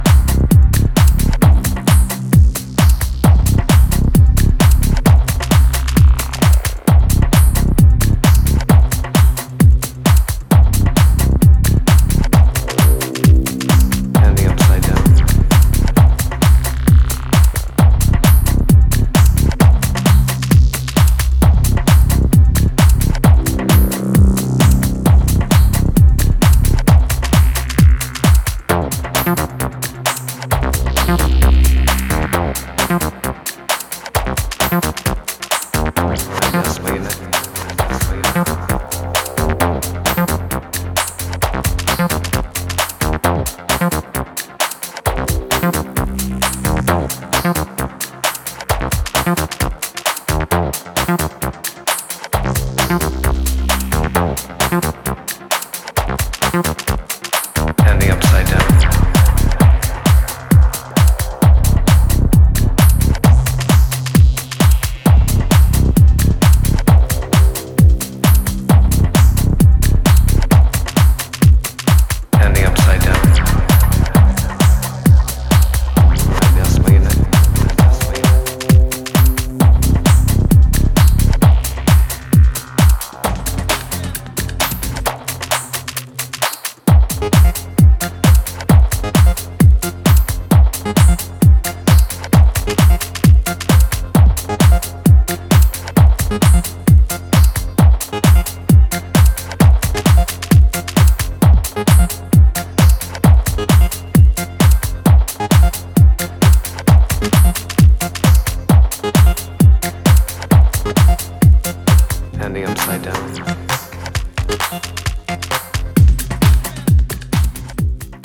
for another trip into tech house